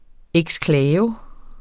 Udtale [ εgsˈklæːvə ]